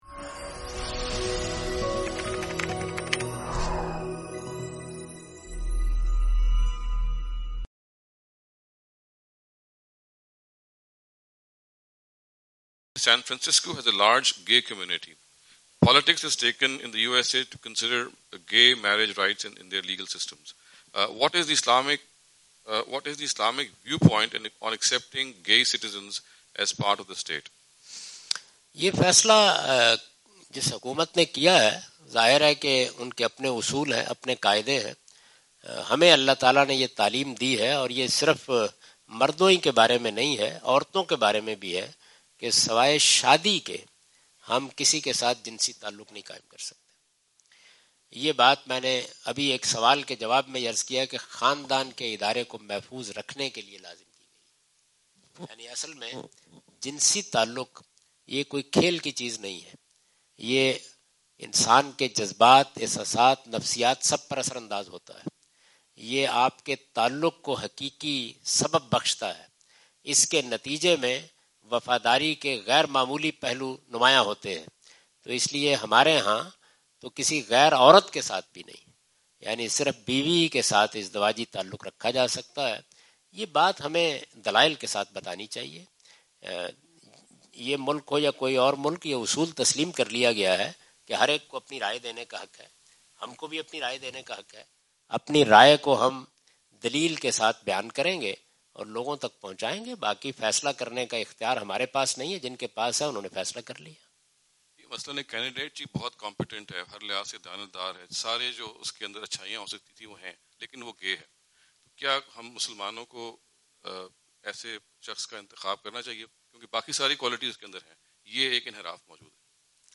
Category: English Subtitled / Questions_Answers /
Javed Ahmad Ghamidi answer the question about "Status of Gay Community in Islamic Countries" during his US visit on June 13, 2015.
جاوید احمد غامدی اپنے دورہ امریکہ 2015 کے دوران سانتا کلارا، کیلیفورنیا میں "اسلامی ممالک میں ہم جنس طبقہ کی حیثیت" سے متعلق ایک سوال کا جواب دے رہے ہیں۔